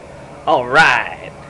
Alright Sound Effect
Download a high-quality alright sound effect.